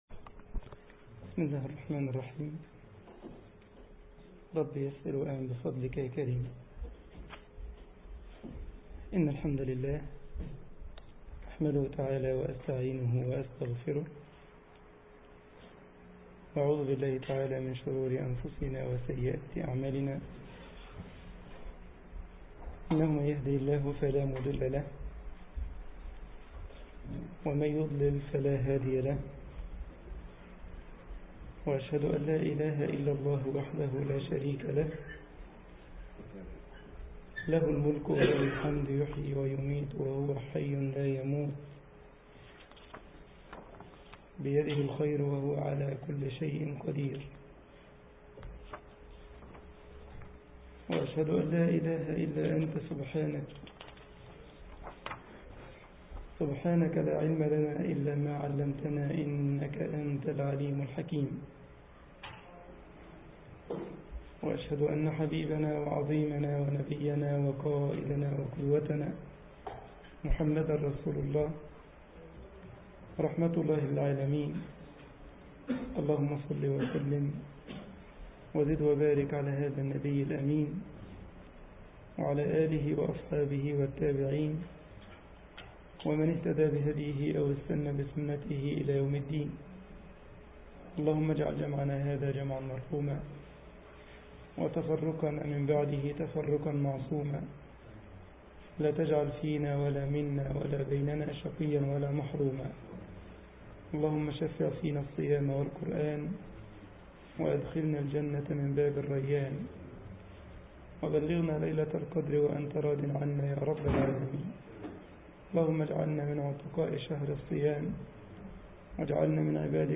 مسجد الجمعية الإسلامية بكايزرسلاوترن ـ ألمانيا درس